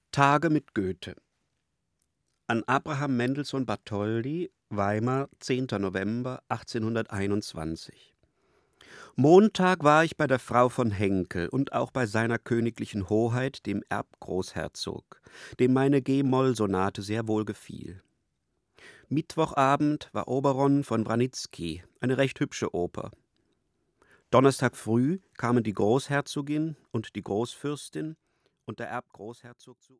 Durs Grünbein - Sprecher